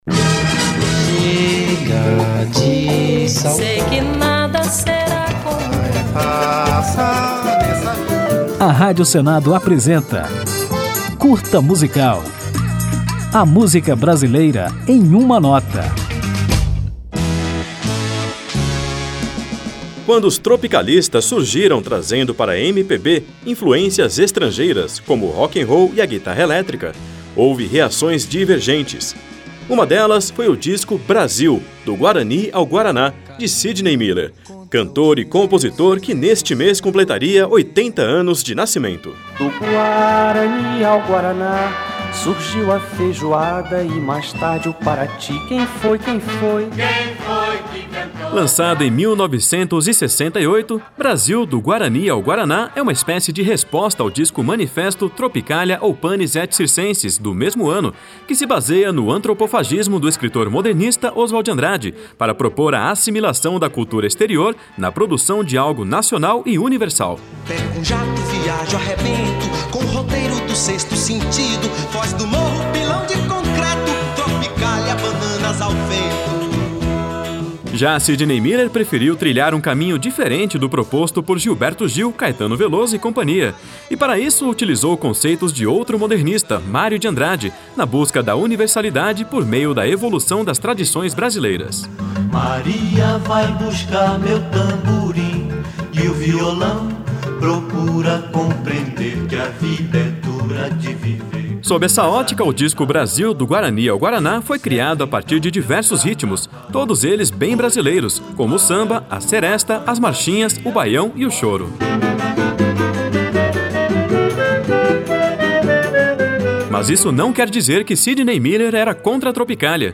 Por isso, o Curta Musical preparou esta homenagem, na qual você vai conhecer um pouco da história do músico carioca e também do álbum Brasil: Do Guarani ao Guaraná, lançado por Sidney em 1968, numa espécie de resposta ao movimento tropicalista, liderado por Caetano e Gil. É deste disco a música Coqueiro Alto, que encerra o programa.
Samba